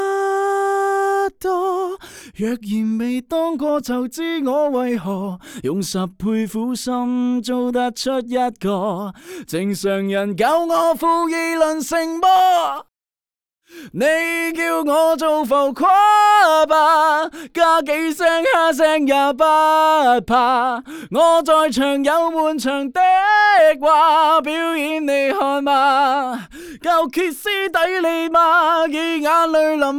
干声试听